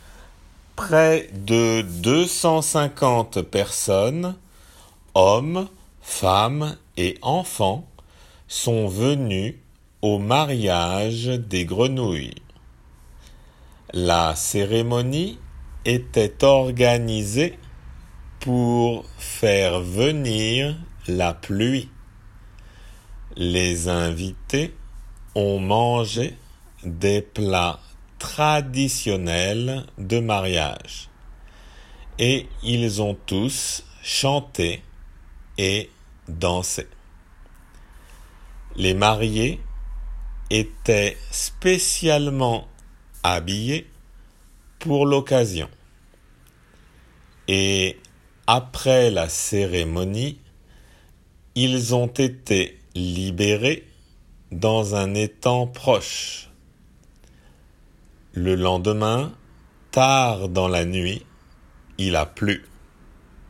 仏検受験用　2級デイクテ12－音声
普通の速さで